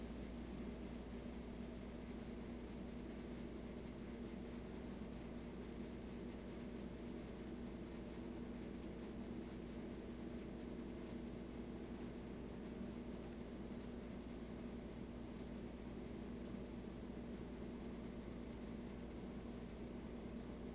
walkInFreezer.wav